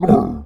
MONSTER_Ugh_03_mono.wav